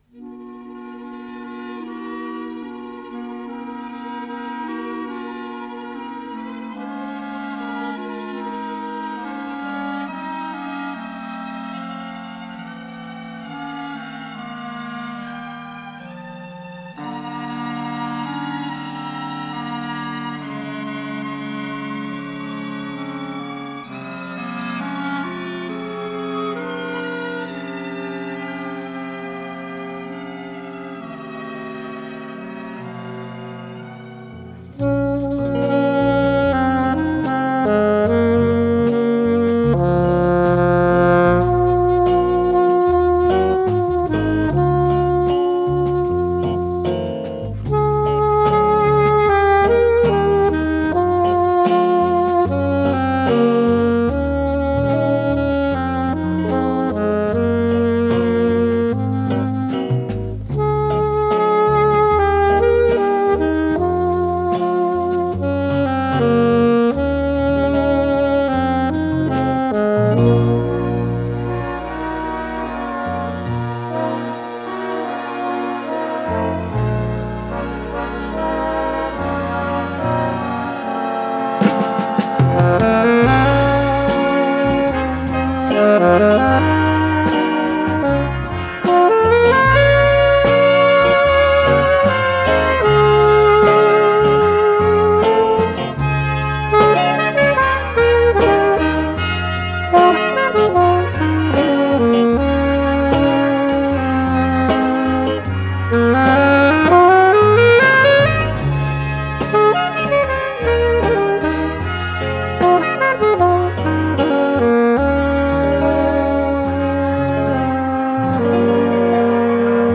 с профессиональной магнитной ленты
саксофон-альт
ВариантДубль моно